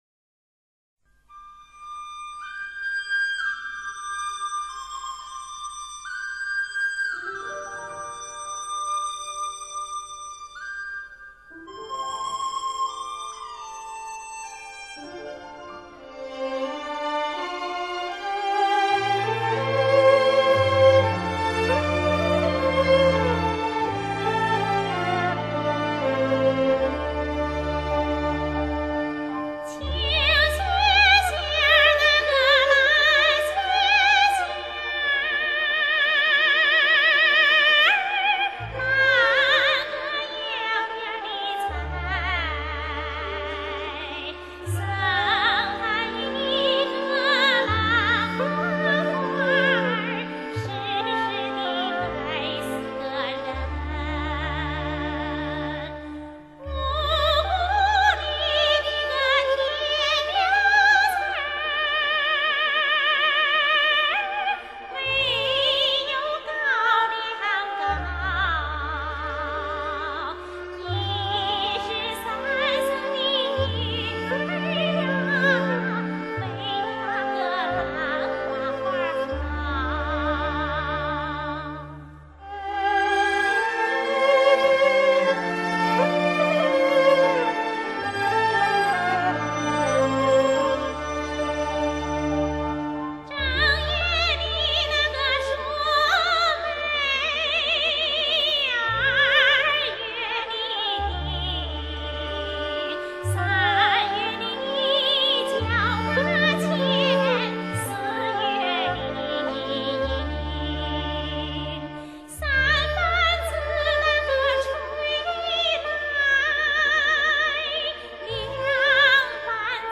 [12/8/2007][陕北民歌]蓝花花——贠恩凤（再送咖啡）
歌曲的旋律属于信天游的曲调，由上、下两句构成，曲调悠扬柔美，节奏舒缓自由，歌词口语化，很具地方特征。